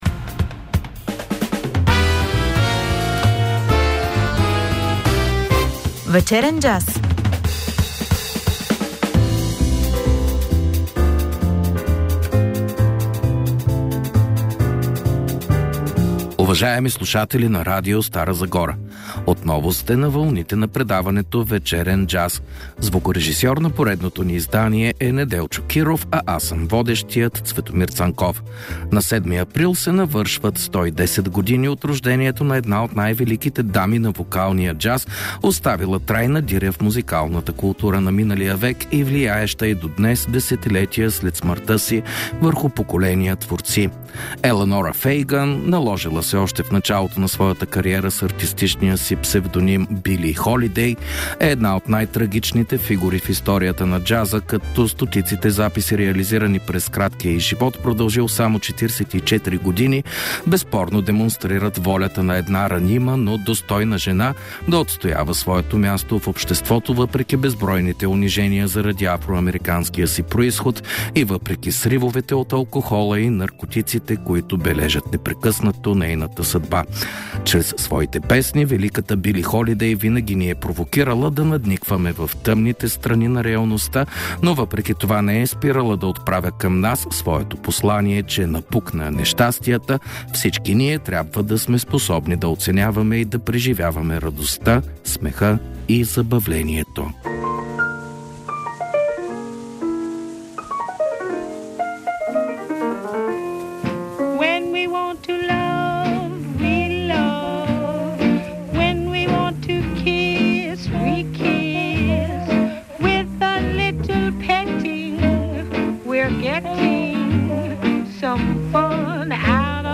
Фокусираме се върху периода 1933-1941 година. Очакват ни и музикални включвания от някои големи звезди инструменталисти от ерата на суинга.